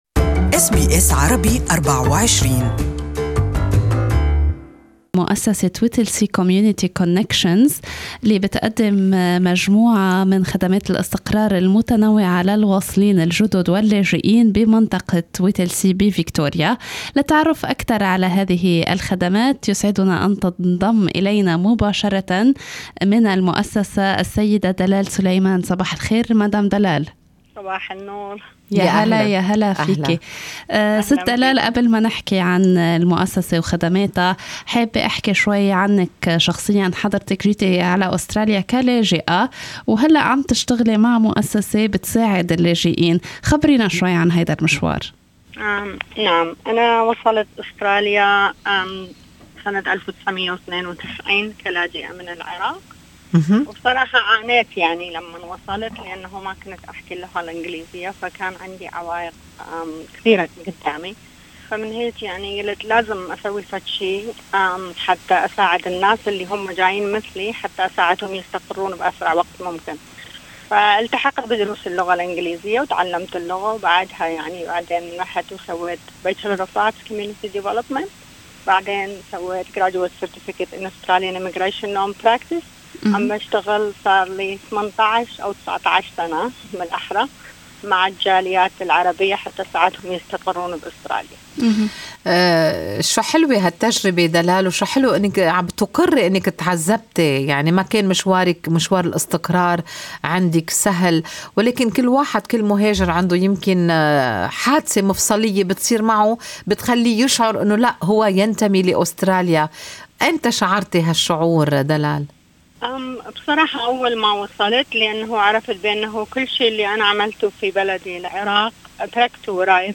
Good Morning Australia interviewed